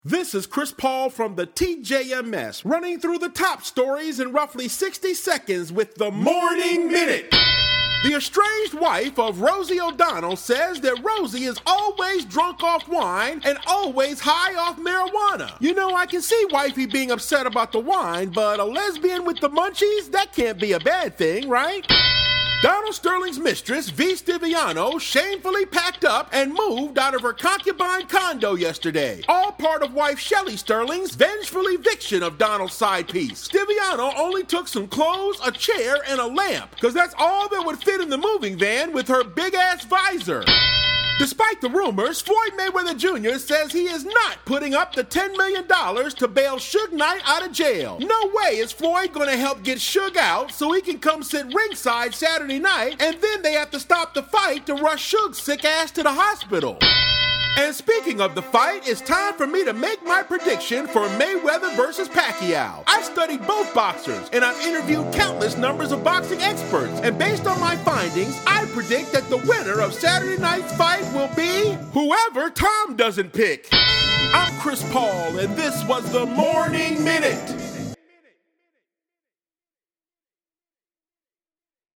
Comedian